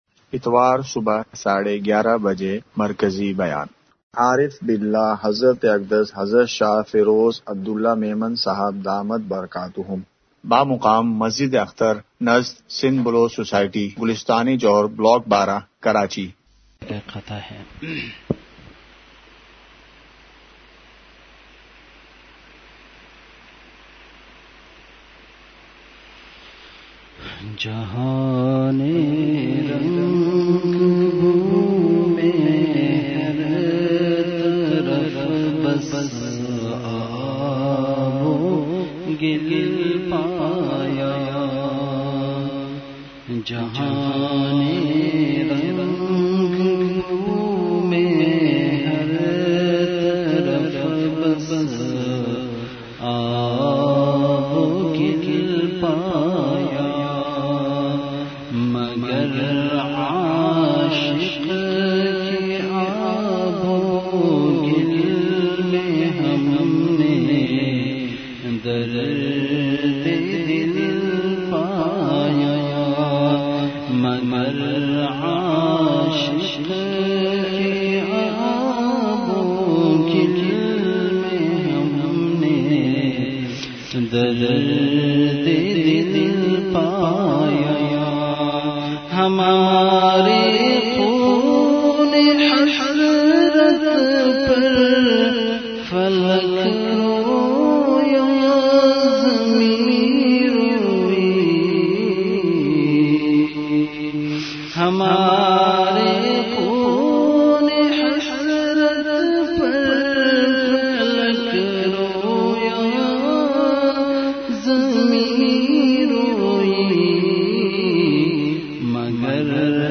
*مقام:مسجد اختر نزد سندھ بلوچ سوسائٹی گلستانِ جوہر کراچی*
*10:06) بیان کے آغاز میں اشعار کی مجلس ہوئی۔۔*